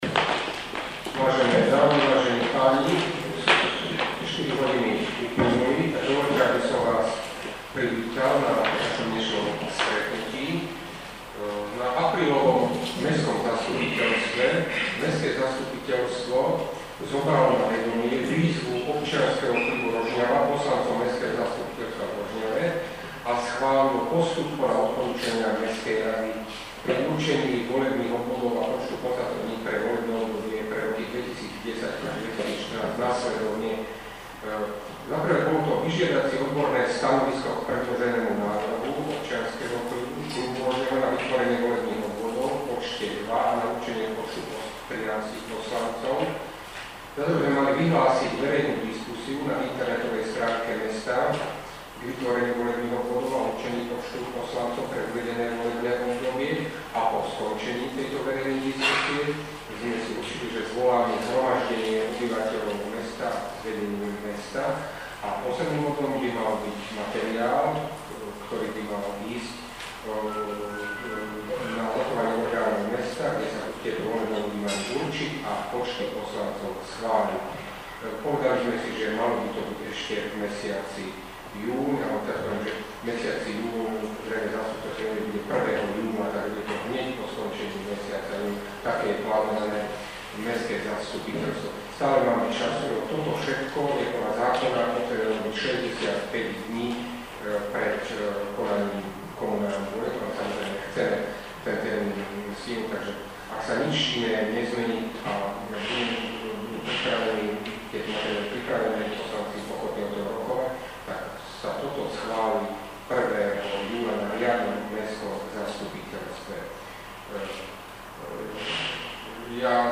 V utorok popoludní sa konalo zhromaždenie obyvateľov mesta k návrhu OK Rožňava. Témou verejnej diskusie, zvolanej samosprávou mesta, bolo Volebné obdobie 2010-2014 – volebné obvody mesta Rožňava a počty poslancov v nich .
Na stretnutie, trvajúce viac ako poldruha hodiny, prišlo asi 7 poslancov zastupiteľstva a približne 20 občanov. Jadro diskusie tvorili aktivisti OK Rožňava.